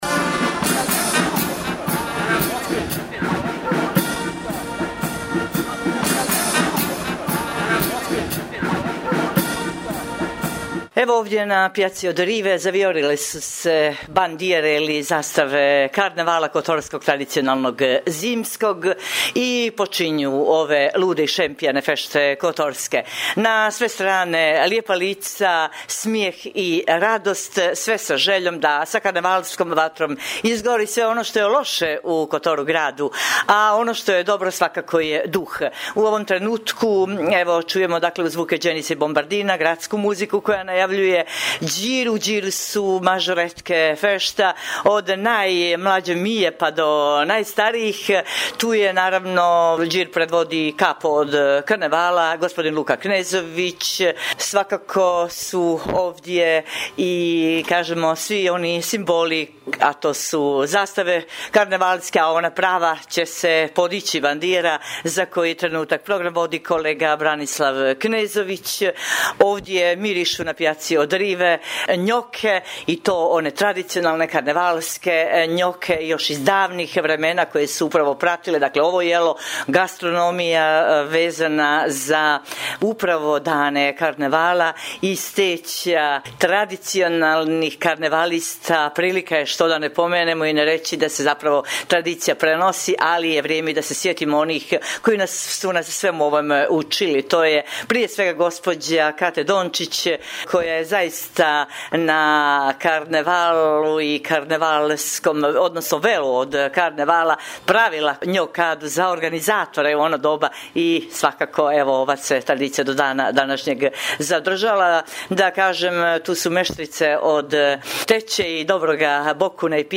Sa otvaranja ovogodišnjih tradicionalnih kotorskih karnevalskih fešti 2020.